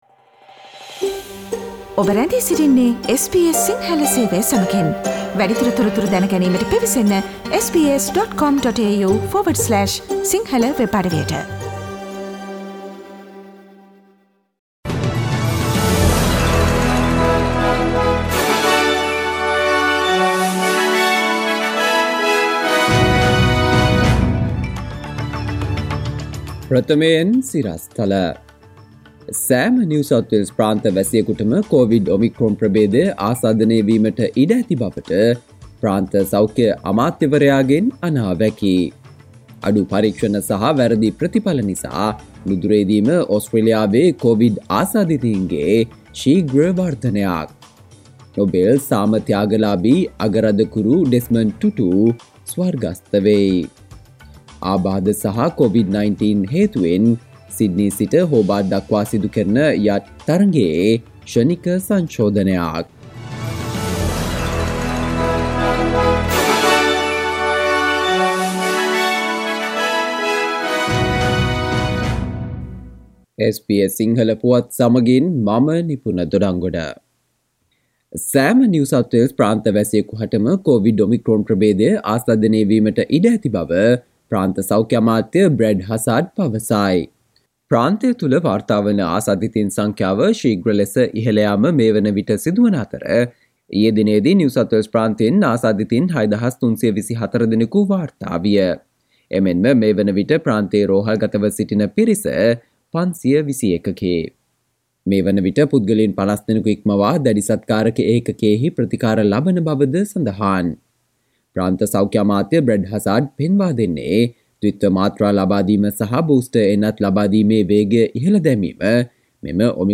සවන්දෙන්න 2021 දෙසැම්බර් 27 වන සඳුදා SBS සිංහල ගුවන්විදුලියේ ප්‍රවෘත්ති ප්‍රකාශයට...